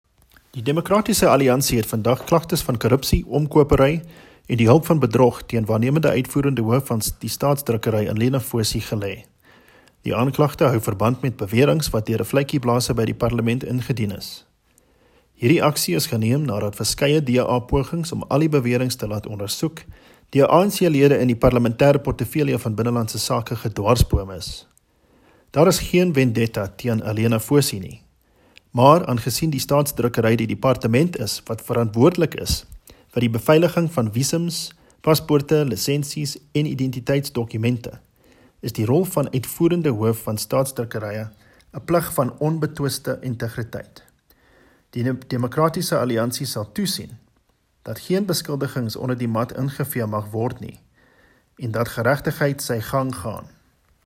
Afrikaans soundbite by Adrian Roos MP, DA Shadow Deputy Minister of Home Affairs.